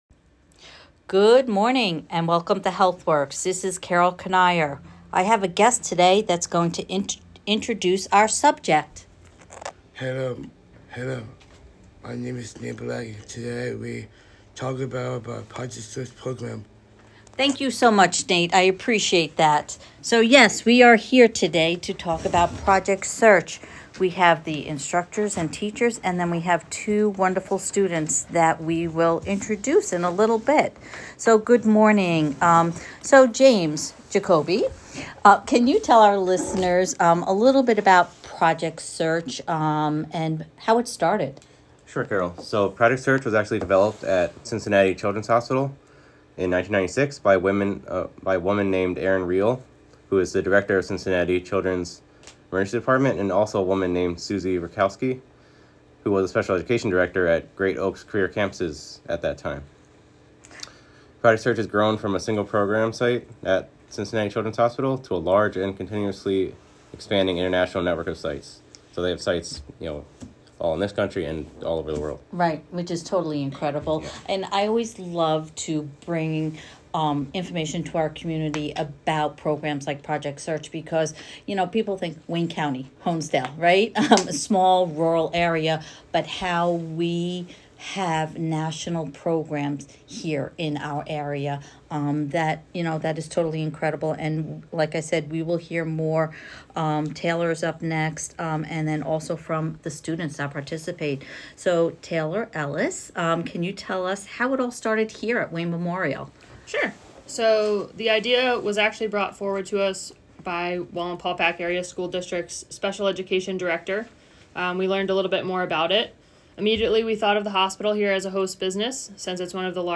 15-minute broadcast Sundays at 7:05 am on WDNH and on Classics 105.